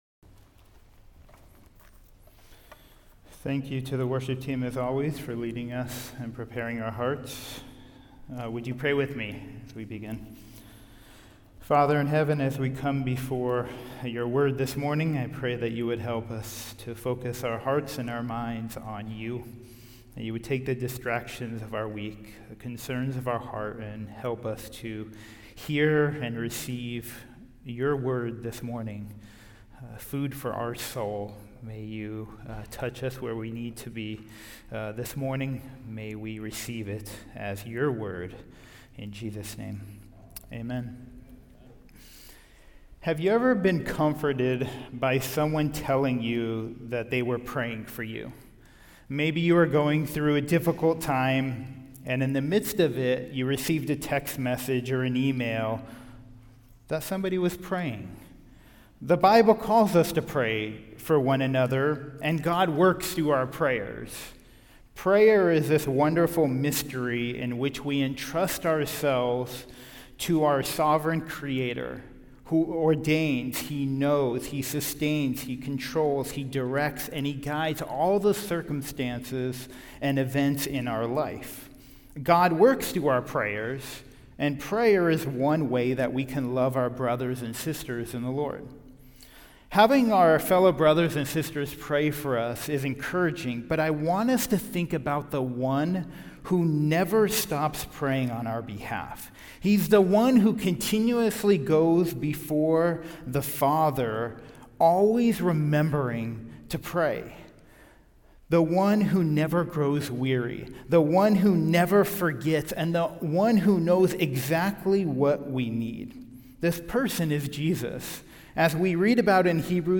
Passage: John 17:1-16 Service Type: Sunday Service